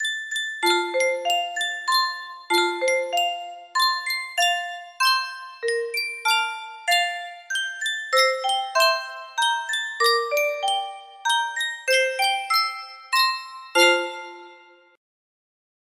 Sankyo Music Box - Brahms' Lullaby STR music box melody
Full range 60